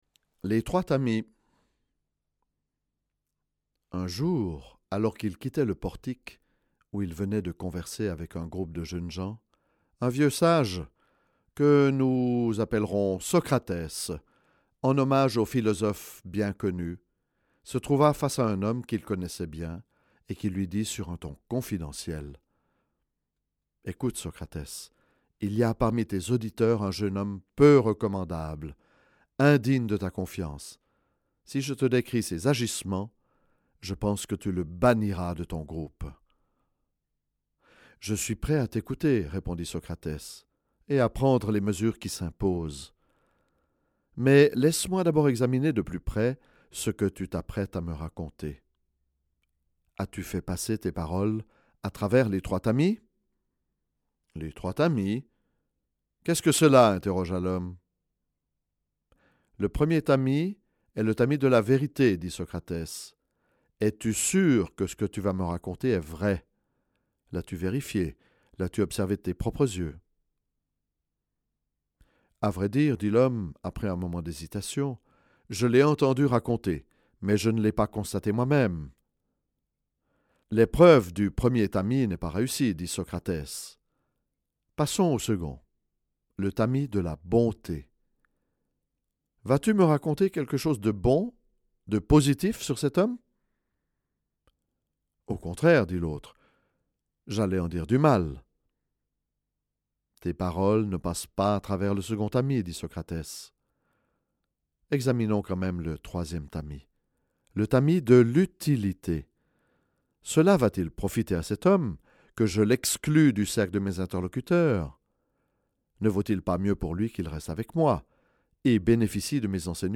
Conte de sagesse d’origine inconnue.